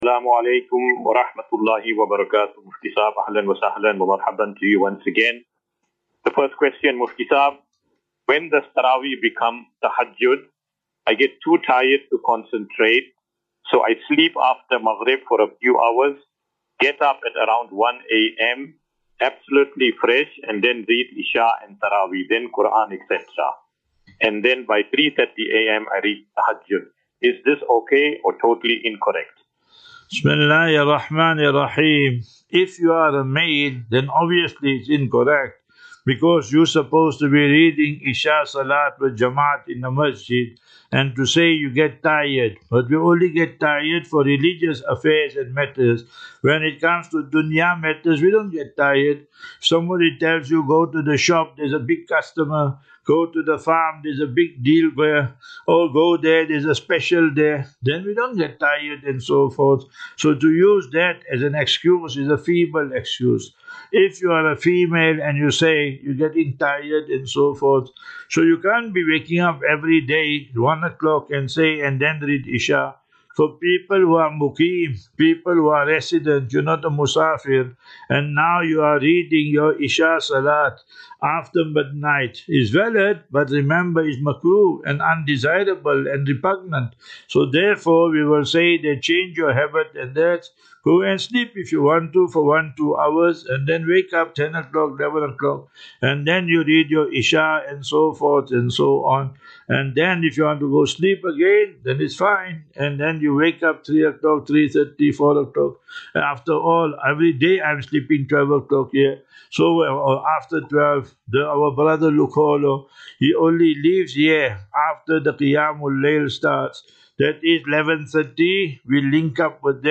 View Promo Continue Install As Safinatu Ilal Jannah Naseeha and Q and A 25 Mar 25 March 2025.